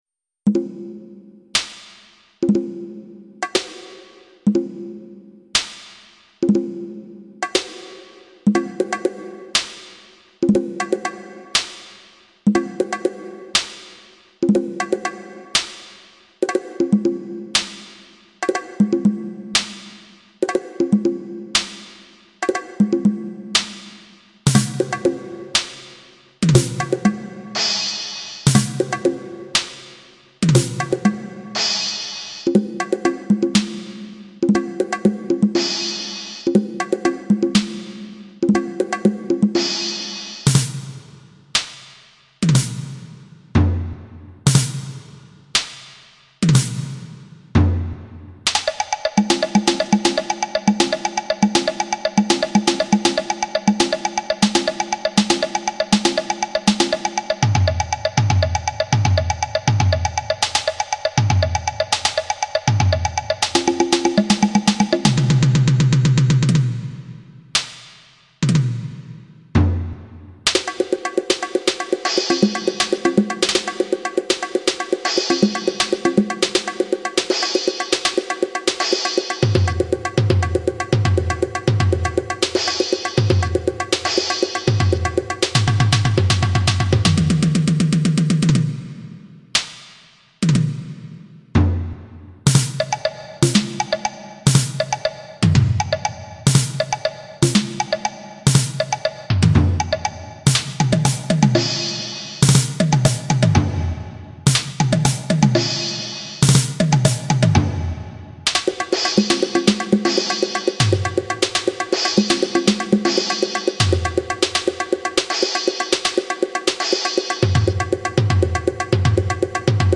Percussion instruments
for three percussionists